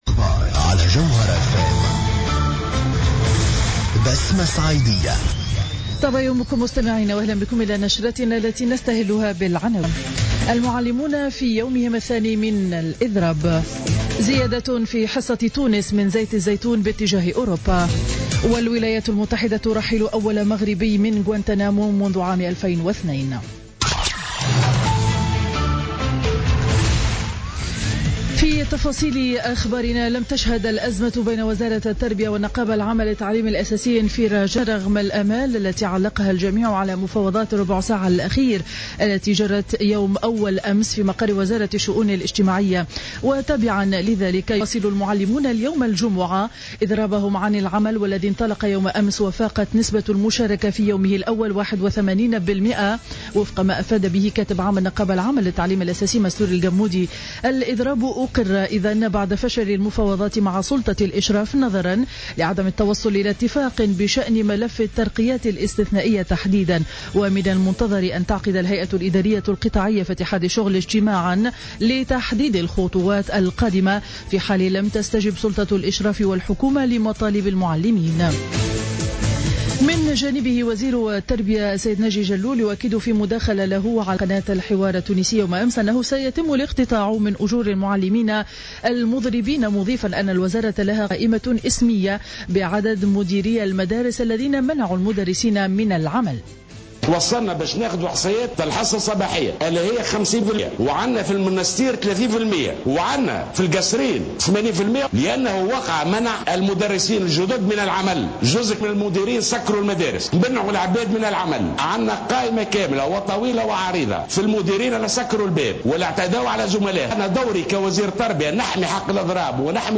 نشرة أخبار السابعة صباحا ليوم الجمعة 18 سبتمبر 2015